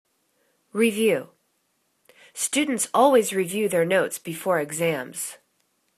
re.view      /ri'vyu:/    n